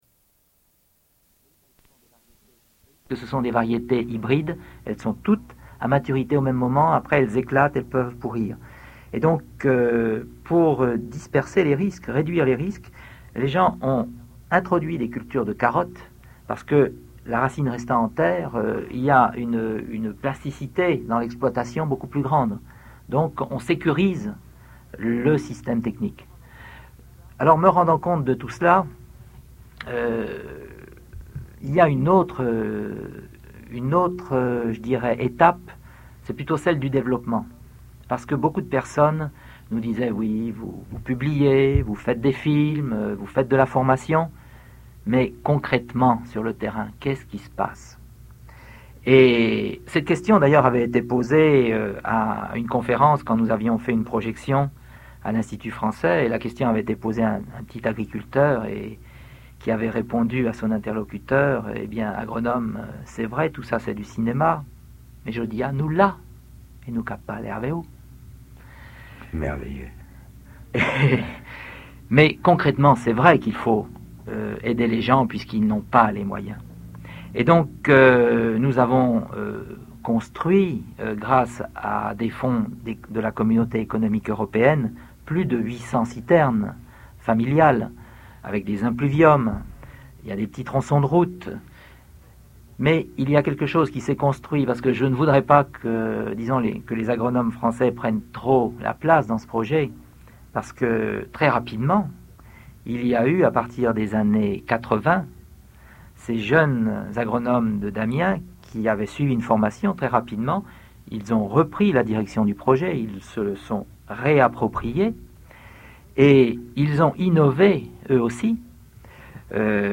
Résumé Dans cet entretien